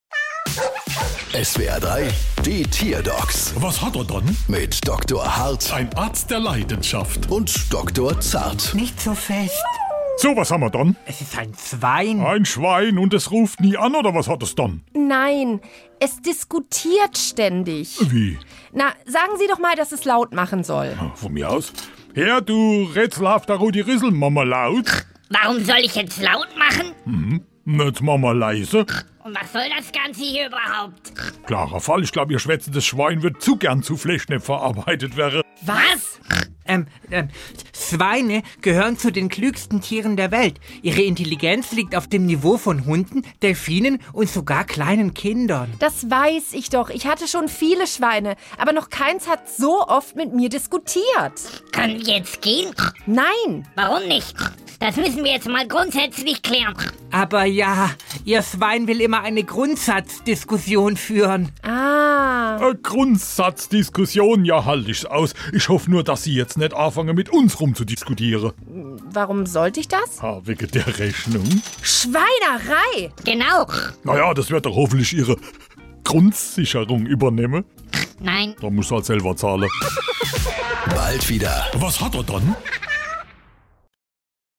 SWR3 Comedy Die Tierdocs: Schwein diskutiert